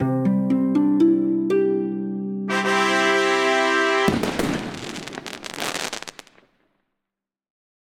bingo_win.ogg